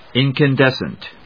in・can・des・cent /ìnkəndésnt/